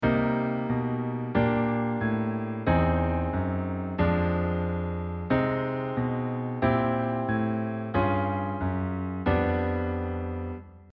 harmonic melody in Cm - parallel real and tonal chord motion over chromatic bassline.mp3